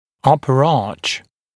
[‘ʌpə ɑːʧ][‘апэ а:ч]верхний зубной ряд